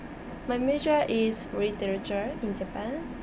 S2 = Japanese female
Discussion: The medial consonant in major is [ʒ] rather than [dʒ], and literature begins with [r] rather than the expected [l].